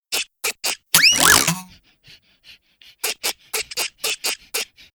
backflip.ogg